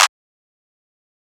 r.i.c.o. clap.wav